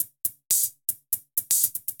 UHH_ElectroHatD_120-02.wav